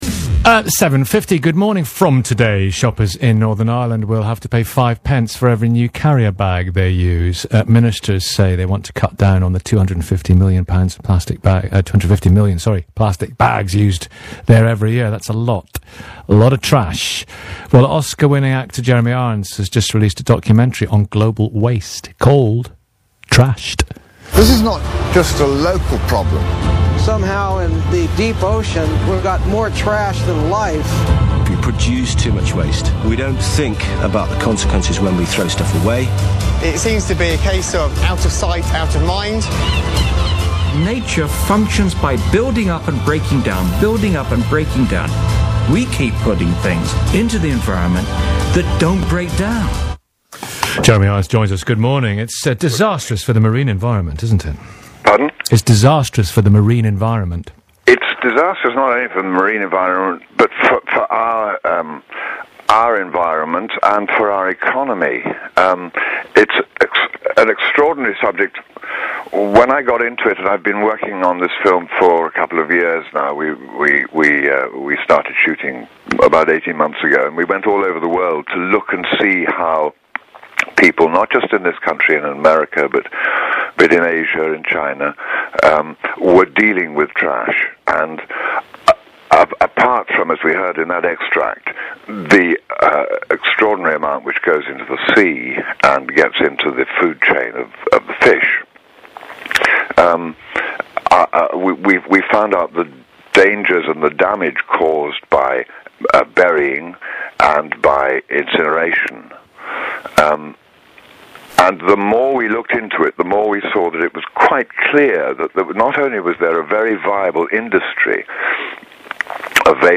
Jeremy Irons was a guest on BBC Radio 5 live Breakfast, on Monday 8 April 2013, to talk about the documentary film Trashed.
jeremy-irons-bbc-radio-5-live-breakfast.mp3